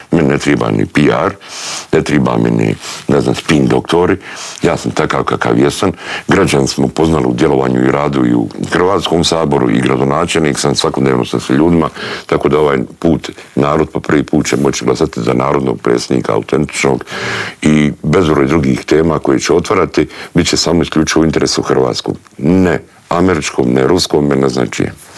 Njihov kandidat Miro Bulj bio je gost u Intervjuu tjedna Media servisa i otkrio zašto se kandidirao i po čemu se izdvaja od ostalih kandidata.
Miro Bulj izjavio je u studiju Media servisa da se kandidirao za predsjednika države jer mu je ‘‘puna kapa‘‘ gledati kako Hrvatska demografski tone i kako se vladajući odnose prema Hrvatskoj vojsci.